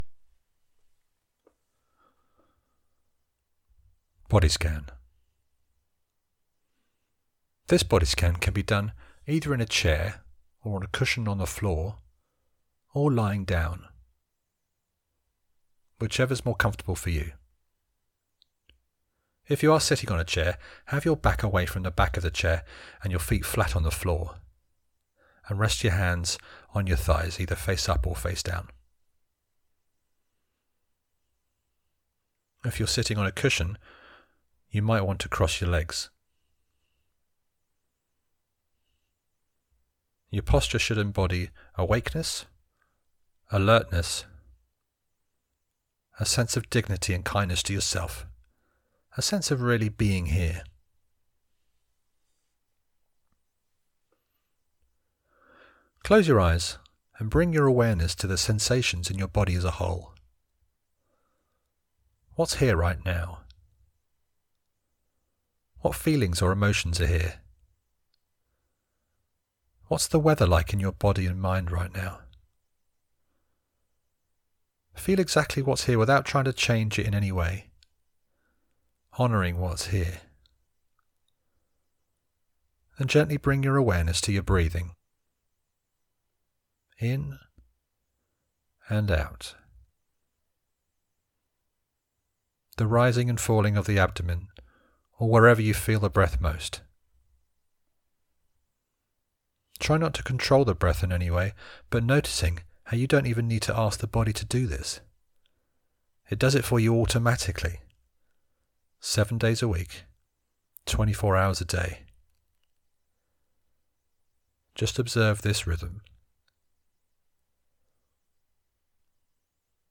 Mindfulness Meditations
body-scan.mp3